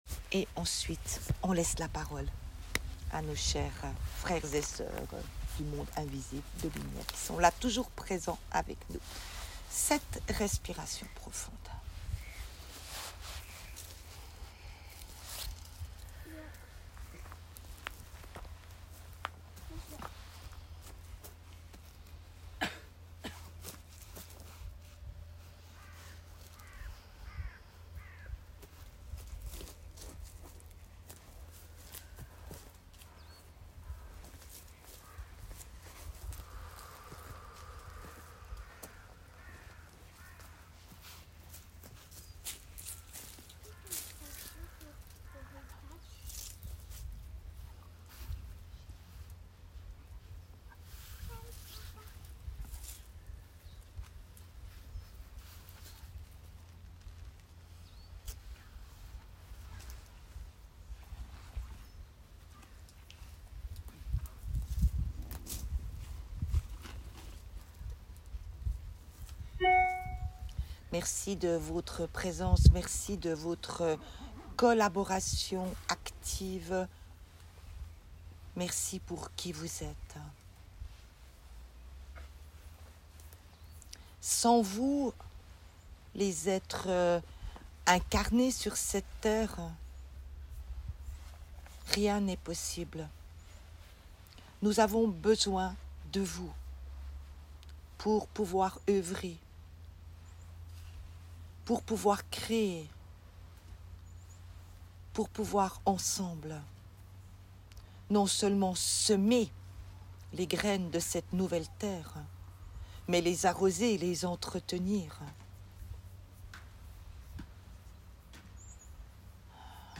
Vous avez également la possibilité de suivre cette transmission et méditation de connexion en audio.